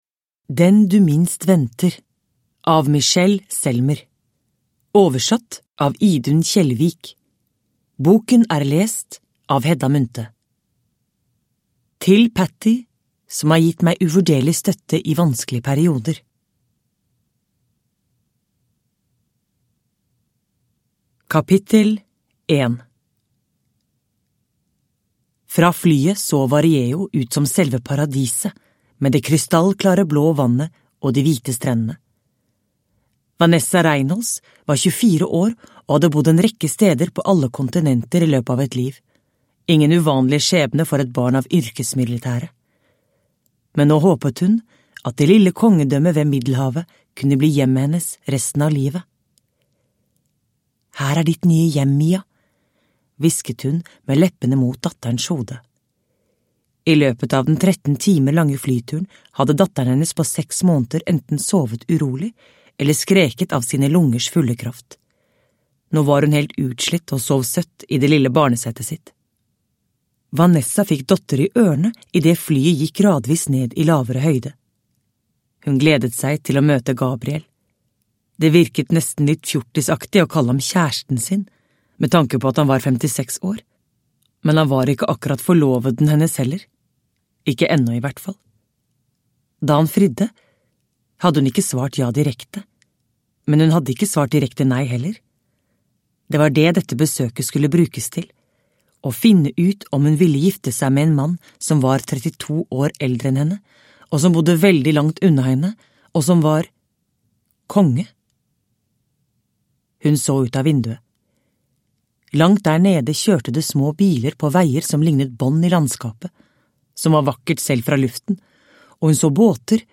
Den du minst venter – Ljudbok – Laddas ner